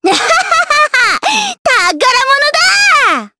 Miruru-Vox_Victory_jp.wav